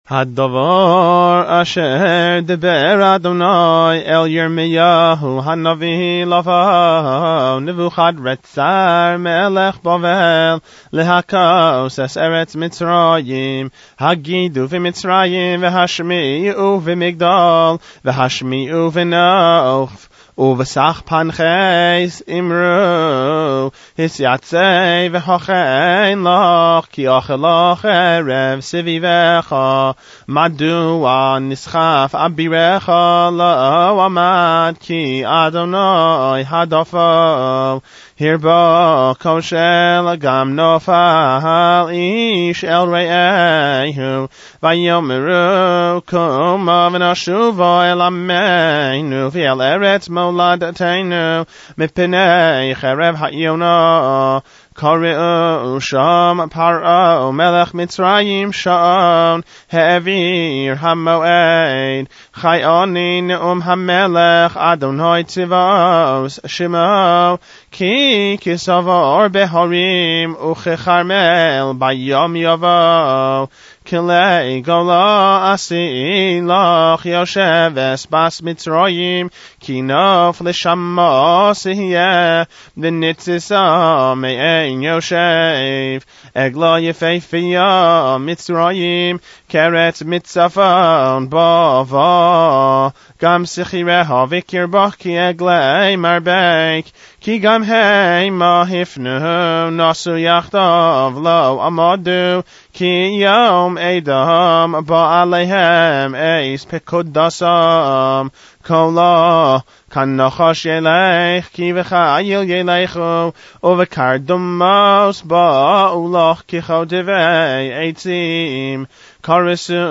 Leins Haftarah